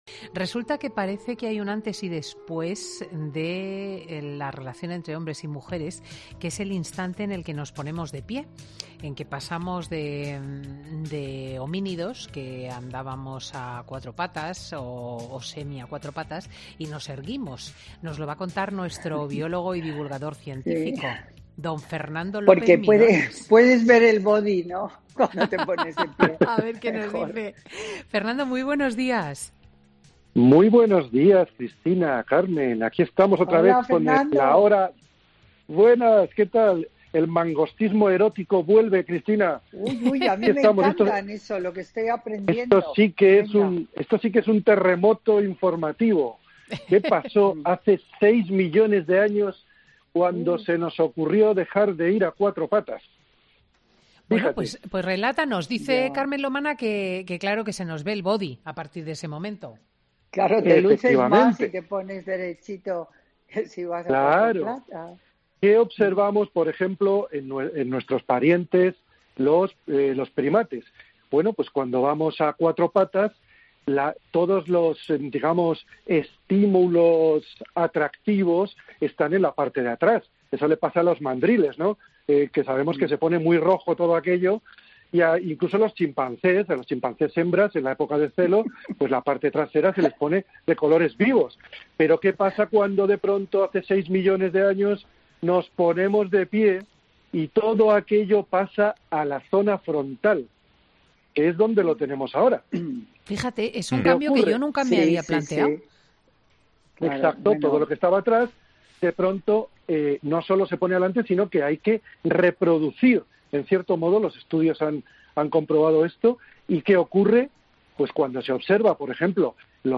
El biólogo y divulgador científico explica en Fin de Semana con Cristina en qué se basa nuestra forma de ligar, según la ciencia